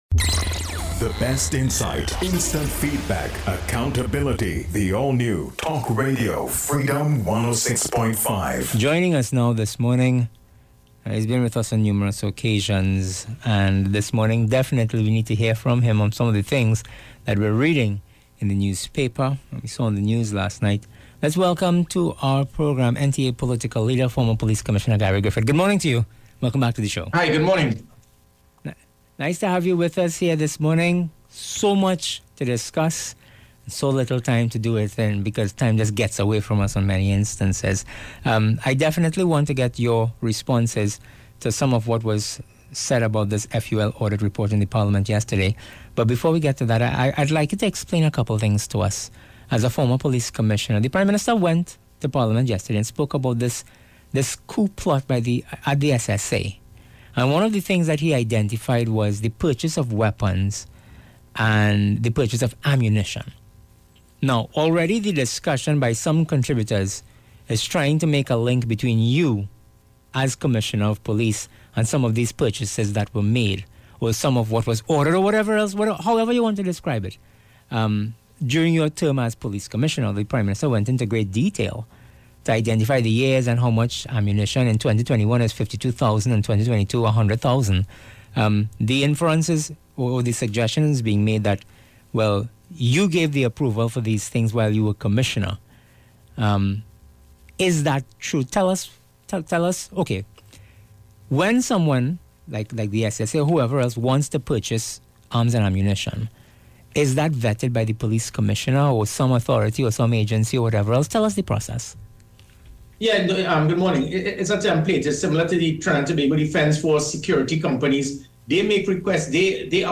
NTA POLITACAL LEADER INTERVIEW.